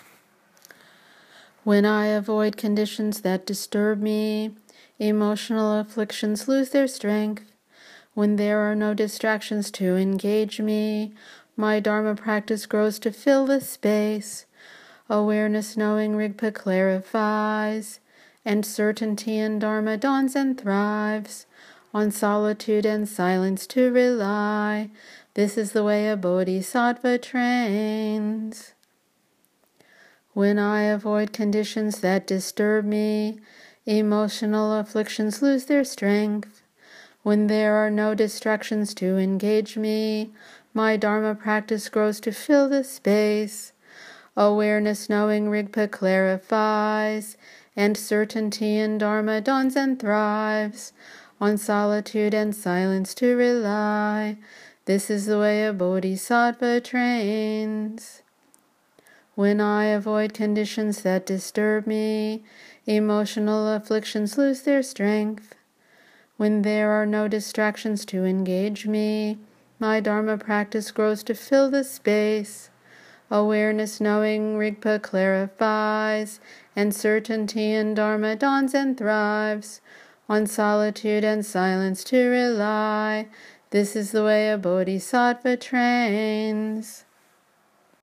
verse 3 chanted 3x.